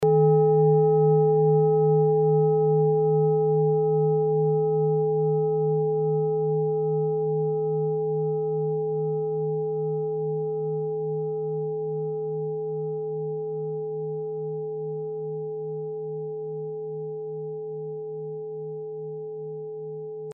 Sie ist neu und wurde gezielt nach altem 7-Metalle-Rezept in Handarbeit gezogen und gehämmert.
Hörprobe der Klangschale
(Ermittelt mir dem Filzklöppel)
klangschale-orissa-18.mp3